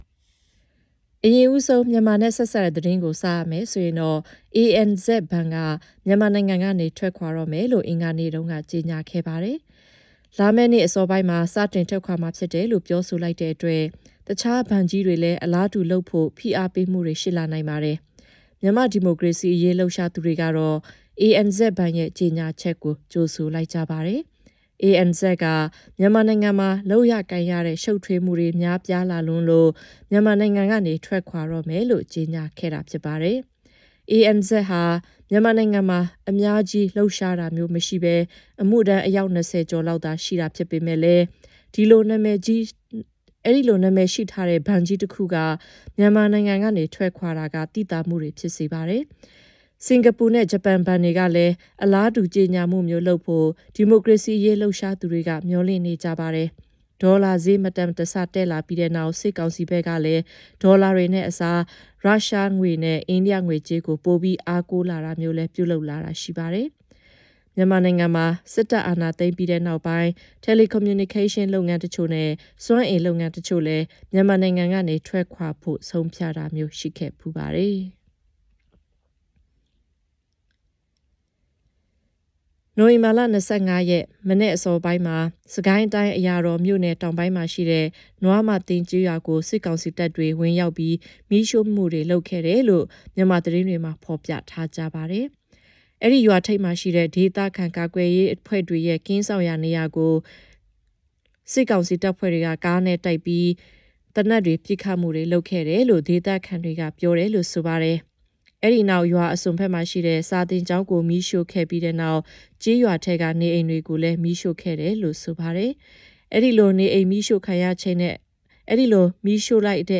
news-bulletins.mp3